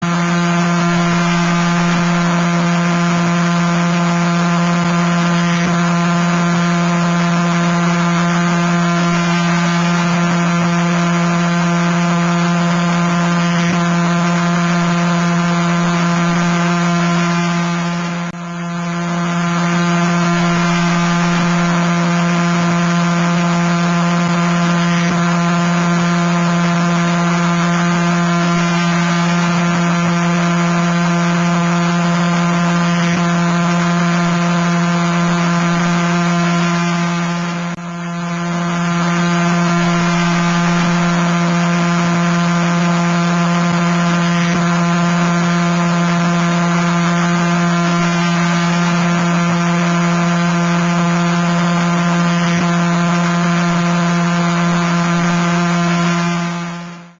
Звуки звёзд, пульсаров
Эти уникальные аудиодорожки, преобразованные из электромагнитных колебаний, идеально подходят для создания атмосферной музыки, монтажа видеороликов, научной работы или глубокой релаксации.